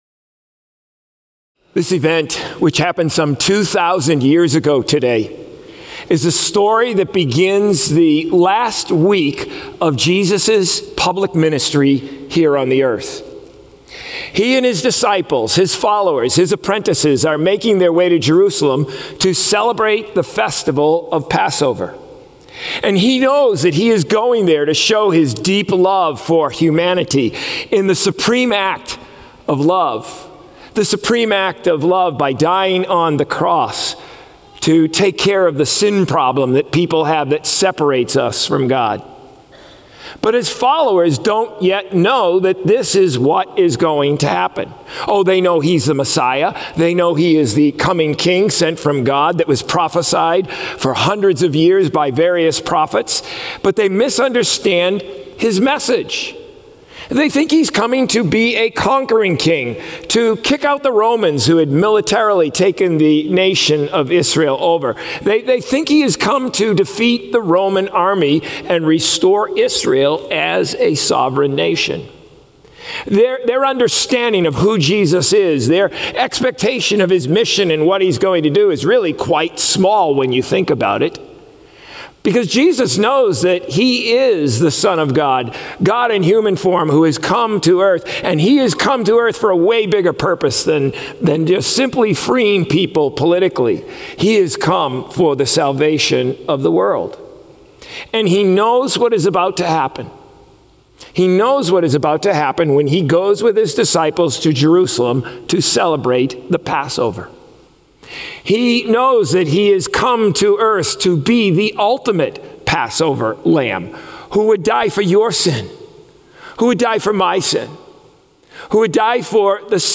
Sermons | COMMUNITY Covenant Church